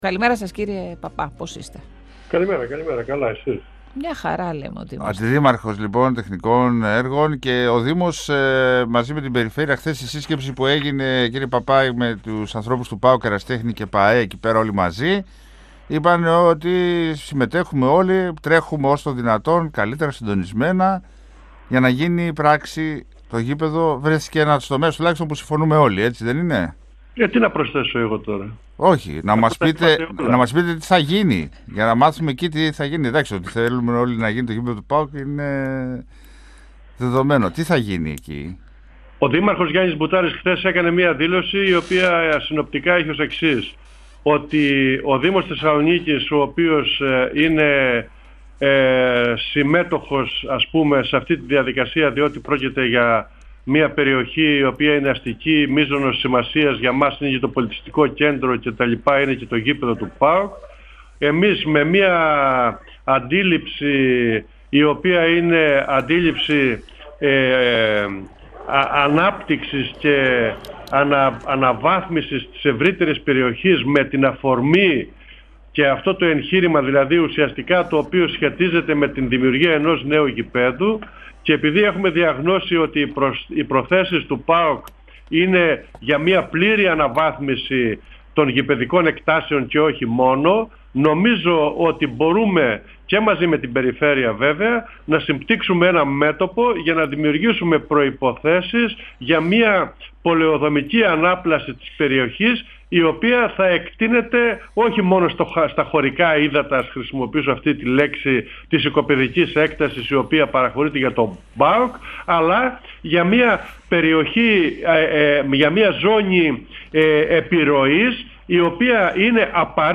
Ο αντιδήμαρχος Τεχνικών Έργων Θανάσης Παππάς, στον 102FM του Ρ.Σ.Μ. της ΕΡΤ3